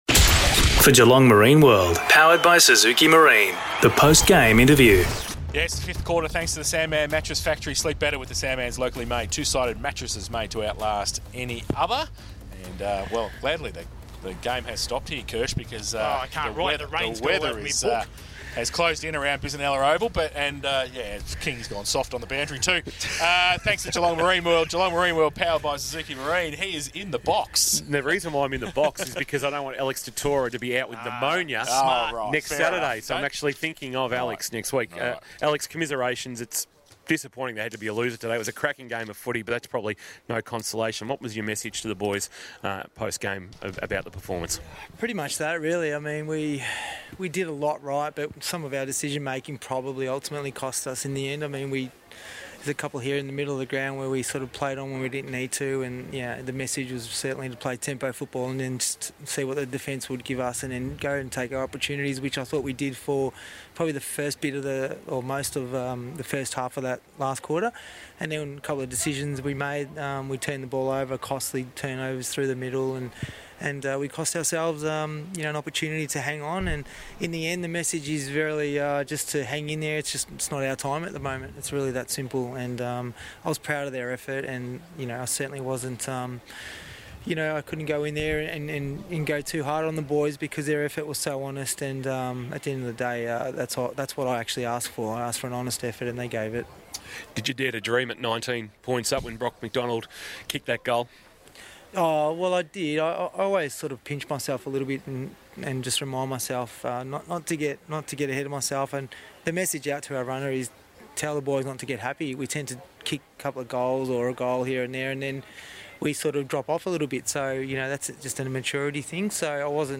2022 - GFL - Round 10 - LARA vs. ST ALBANS: Post-match Interview